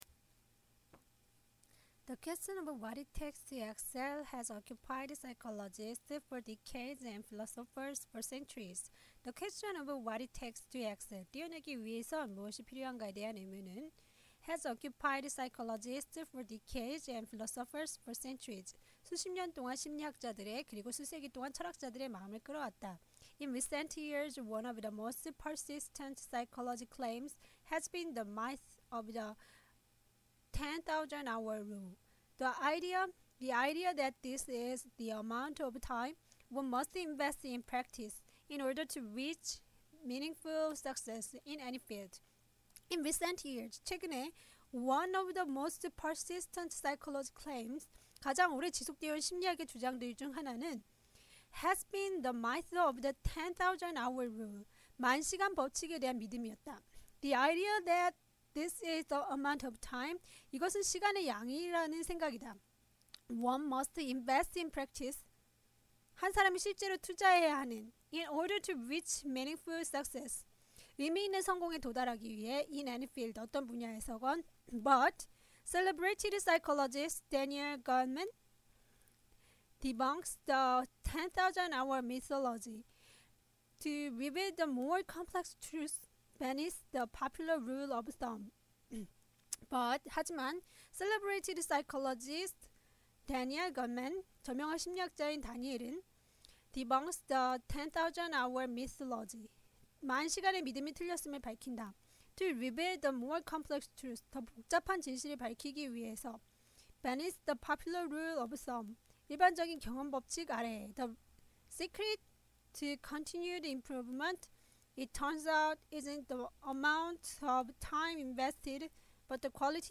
English Reading Practice - 0008 Success Rule